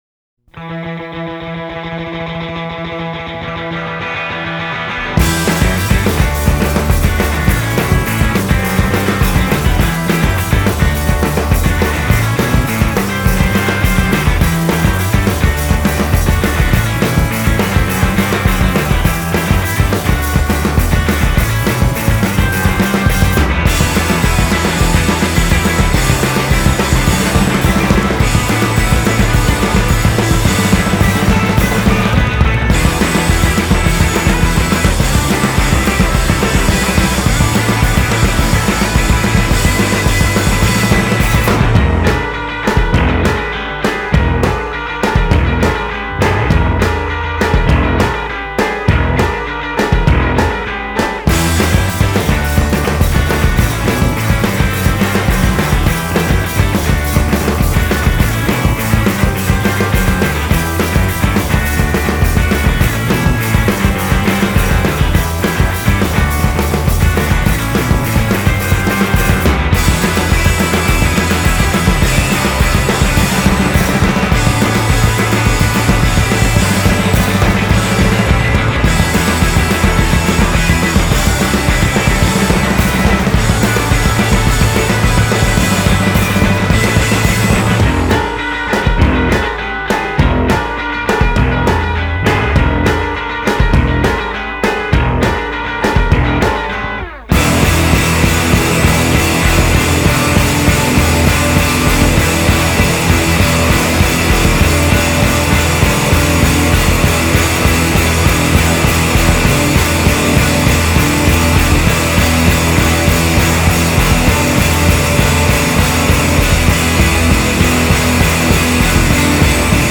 And it wasn’t’ until I found the instrumental track five
alternative rock